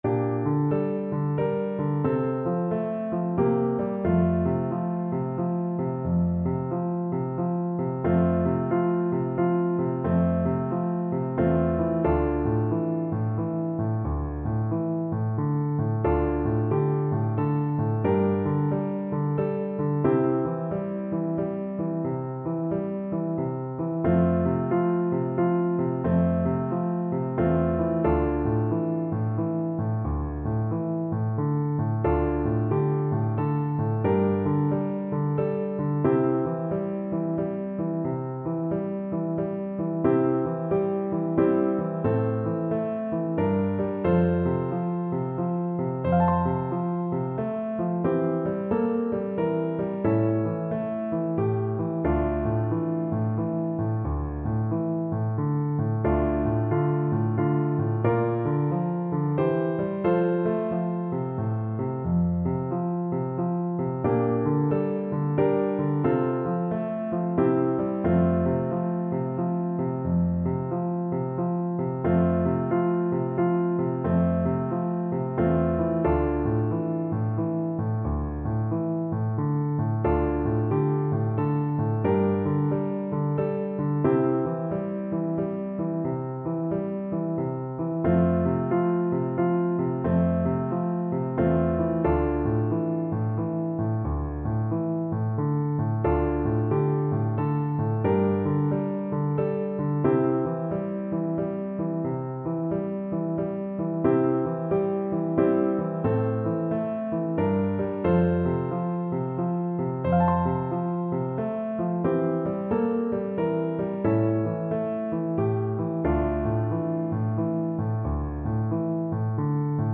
No parts available for this pieces as it is for solo piano.
F major (Sounding Pitch) (View more F major Music for Piano )
=90 Andante, gentle swing
3/4 (View more 3/4 Music)
Piano  (View more Easy Piano Music)
Traditional (View more Traditional Piano Music)
un_canadien_errant_PNO.mp3